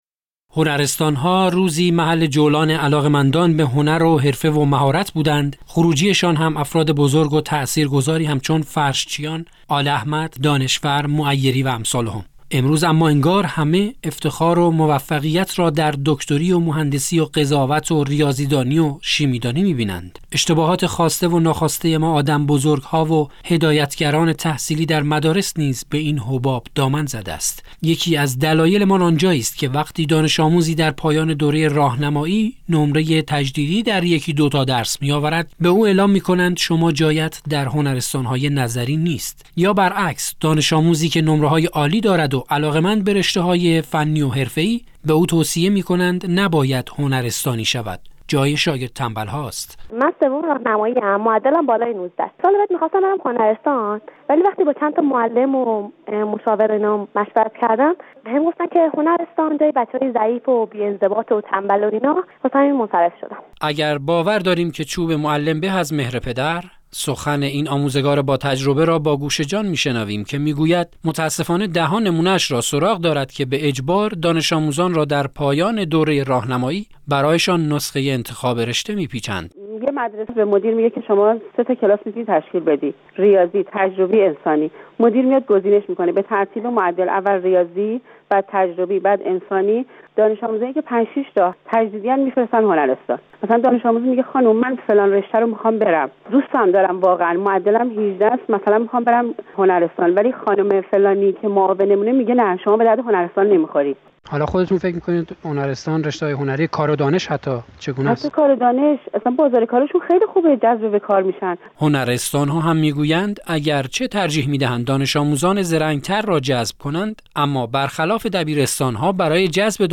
"گزارش شنیدنی" از ثبت نام اجباری دانش آموزان در هنرستان - تسنیم